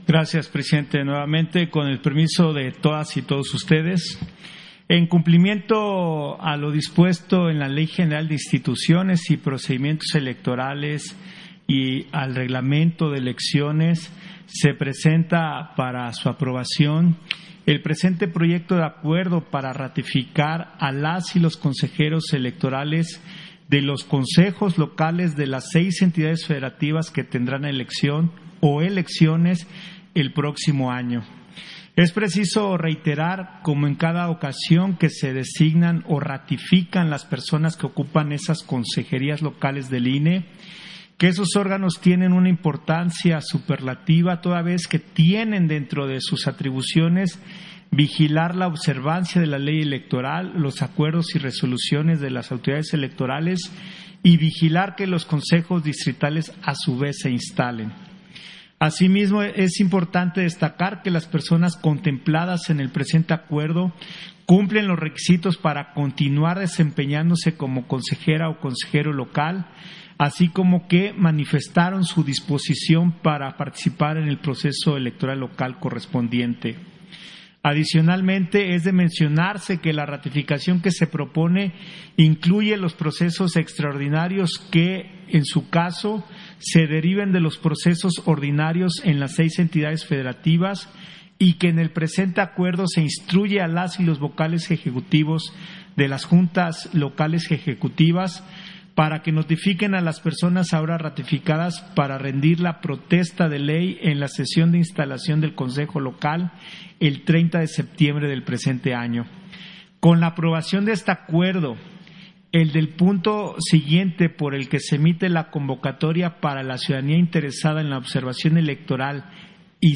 Intervención de José Roberto Ruiz, en Sesión Extraordinaria, por el que se ratifica a las y los Consejeros Electorales Locales, para el Proceso Electoral 2021-2022